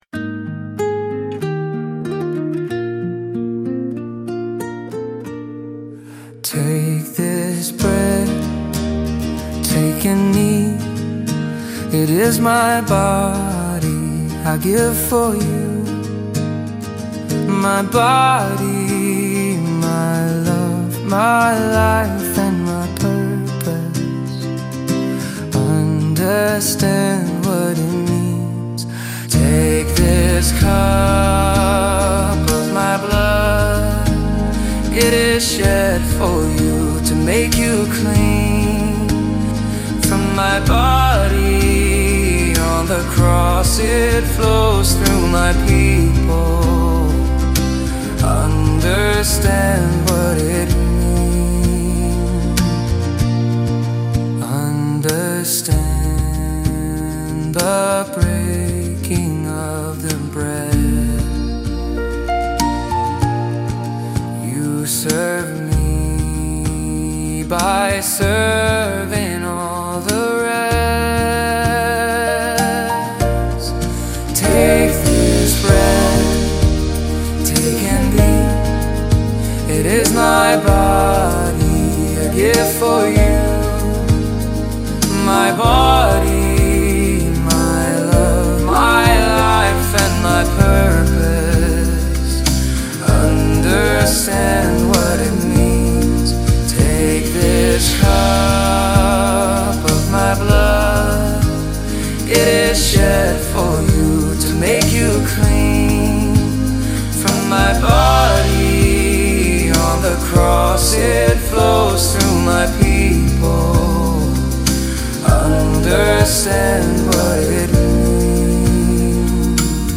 Mainstream